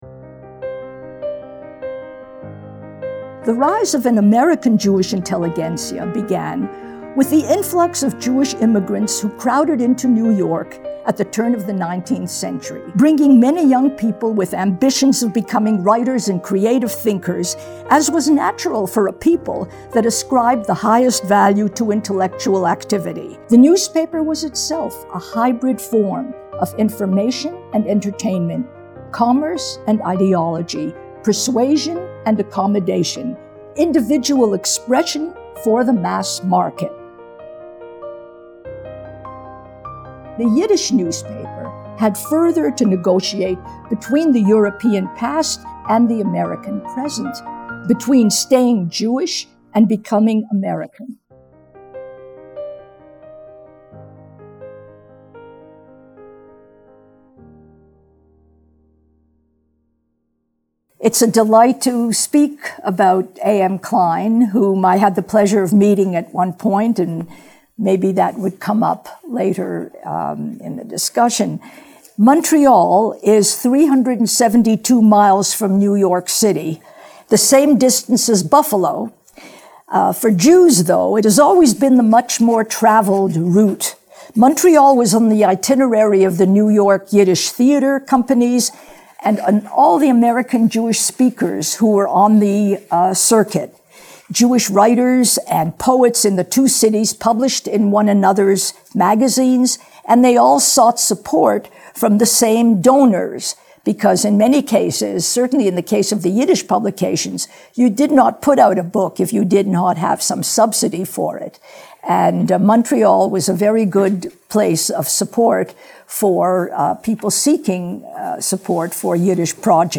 Prior to Leonard Cohen, A.M. Klein was Canada's most famous Jewish poet. In this lecture, Professor Wisse explains how Klein's life and career in Canada can help illuminate New York's great Jewish wri...